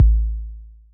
Blow Kick.wav